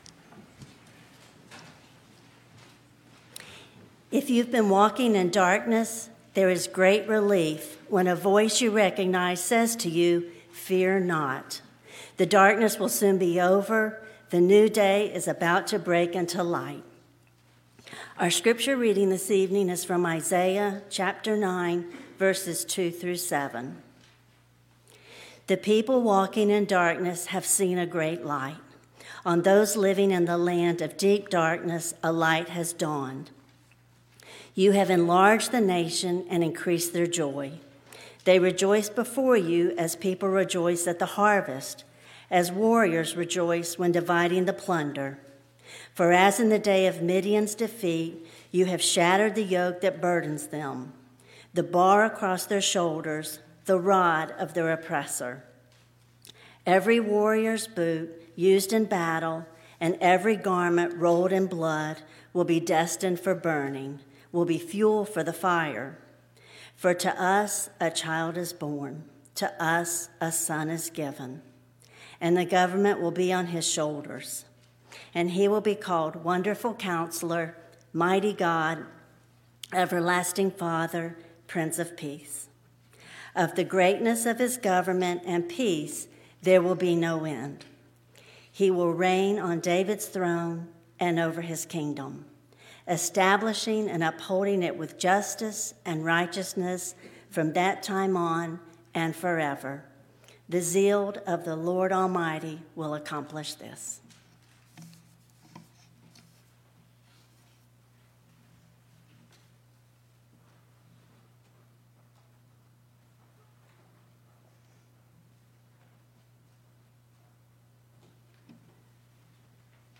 Christmas Eve Candlelight Service
Christmas-Eve-Candlelight-Service-Lighting-of-the-Christ-Candle-and-Sermon.mp3